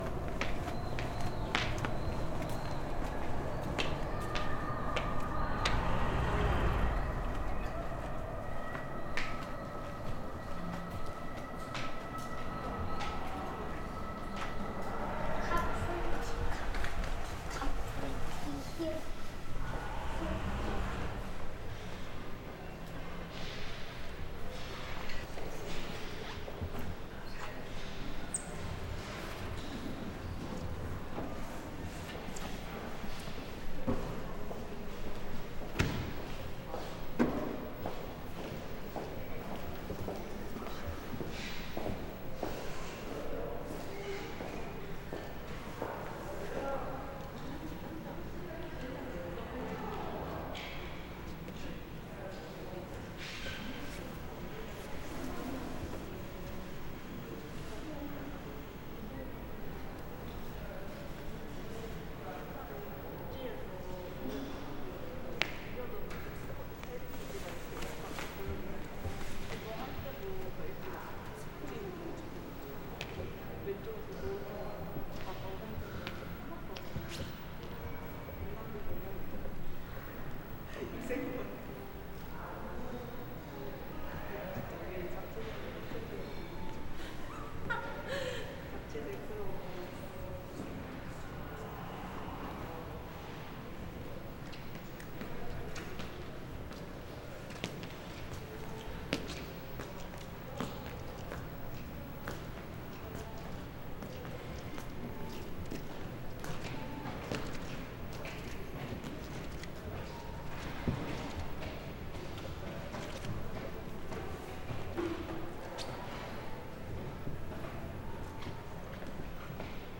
로비출입문에서부터.mp3